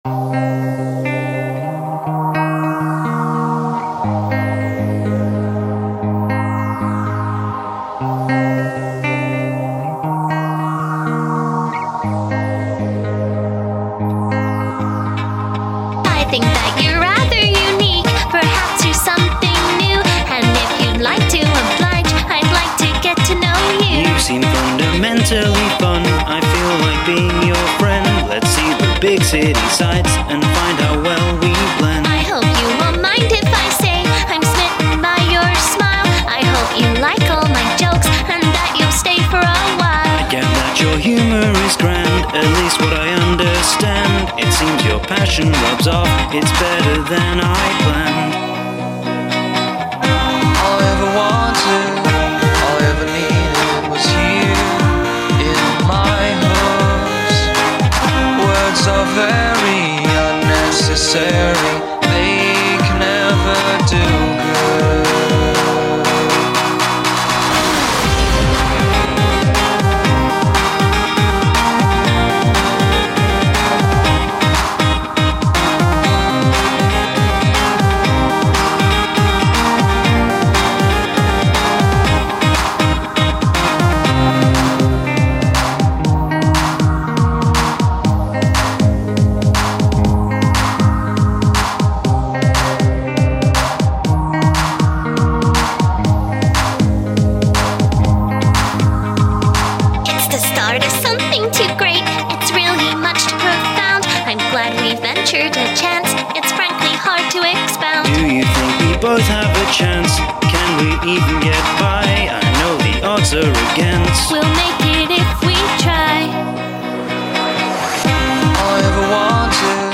Other songs mashed up
Synths/Drums/Concept
Guitars/Concept/Structure